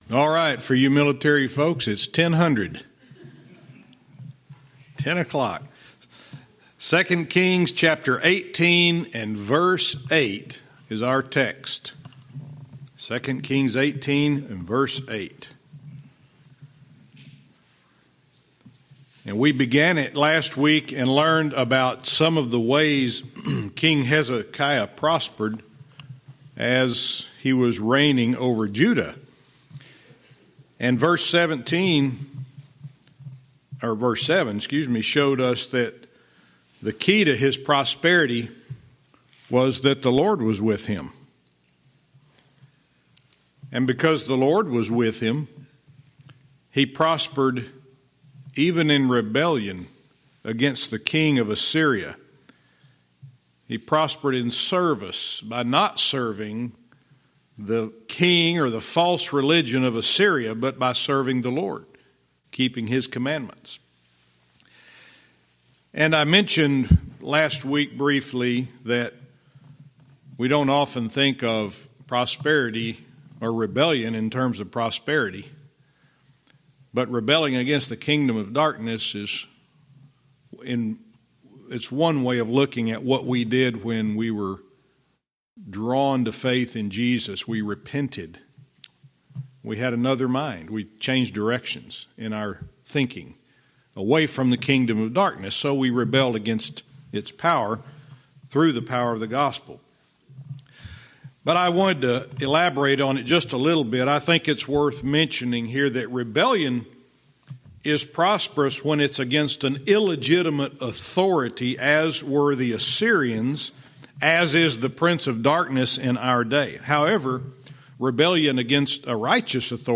Verse by verse teaching - 2 Kings 18:8-15